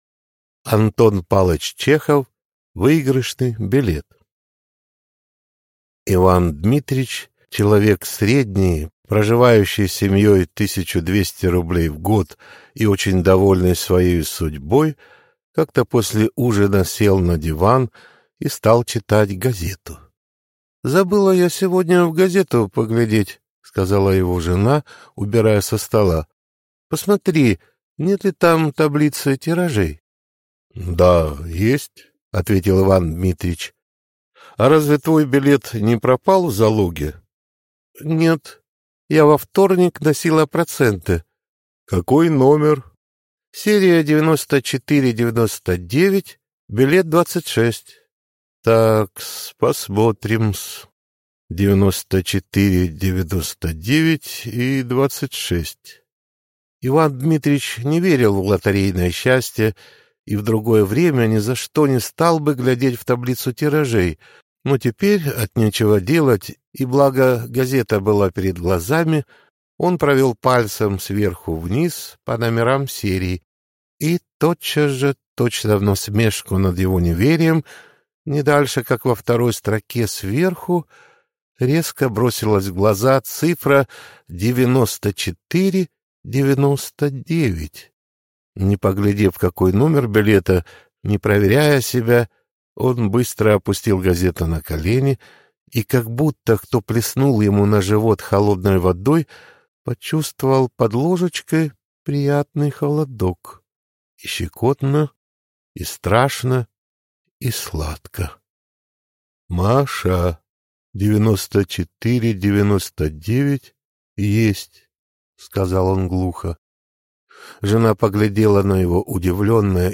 Аудиокнига Выигрышный билет | Библиотека аудиокниг